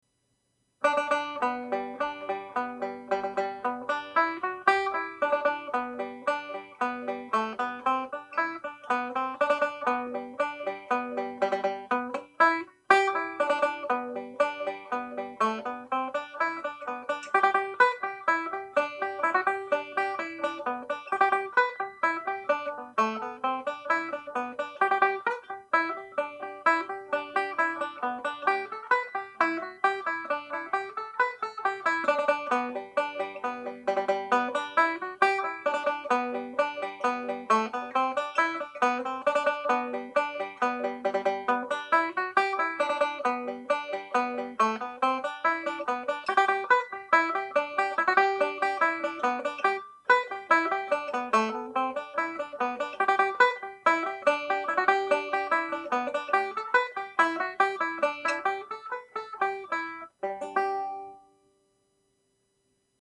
Reel (G Major)
played with triplets